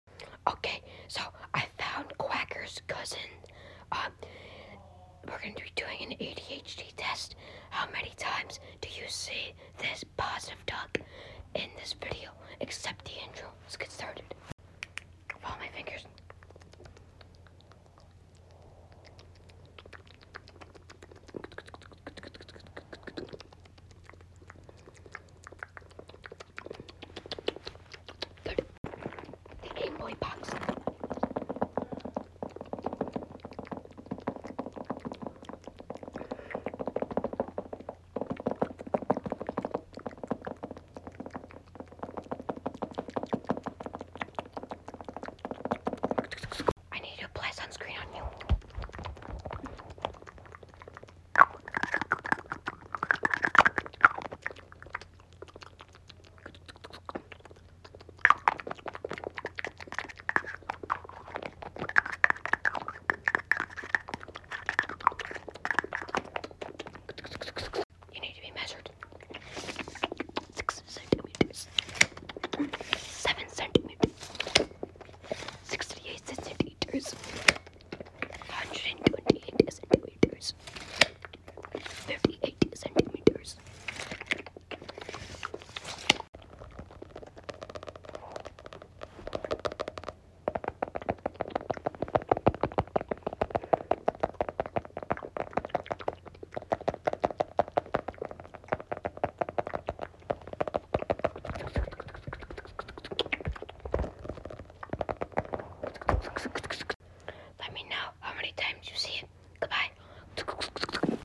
QUACKERS COUSIN (ADHD ASMR TEST) sound effects free download